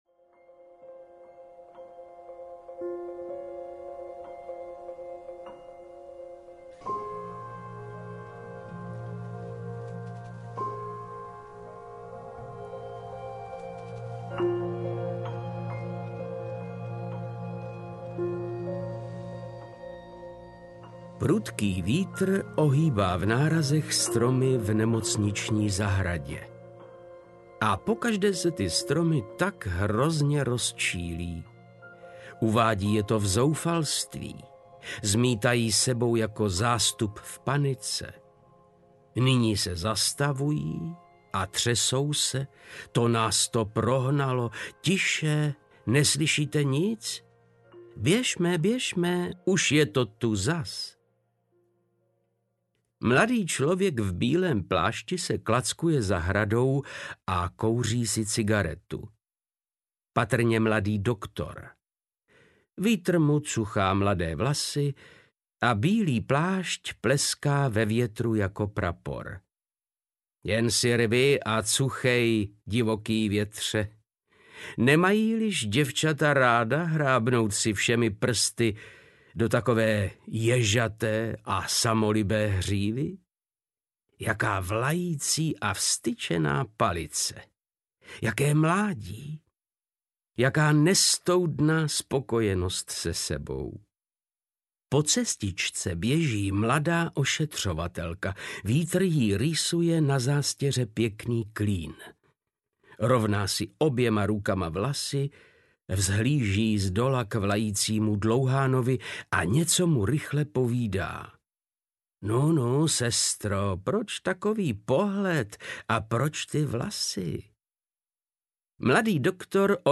Povětroň audiokniha
Ukázka z knihy
• InterpretVáclav Knop